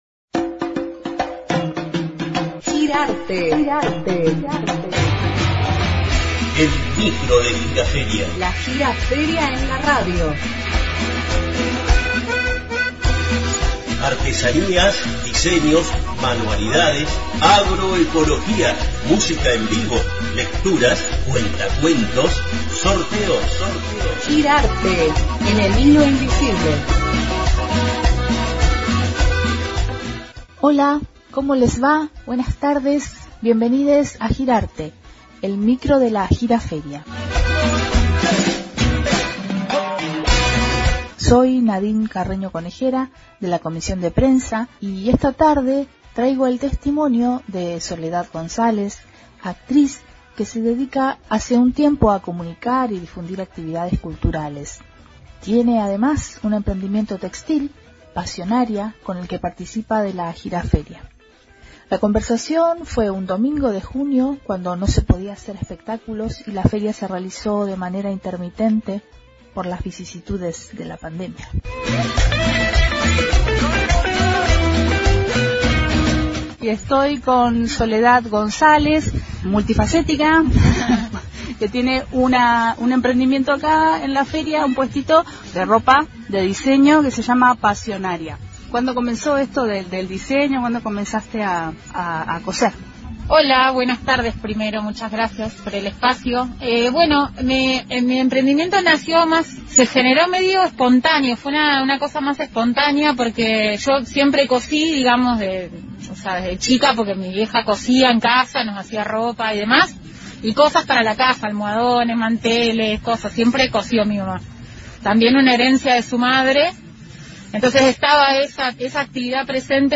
Espacio deradiodifusión de la Gira Feria para conocer todas las actividades y a les feriantes que le dan vida.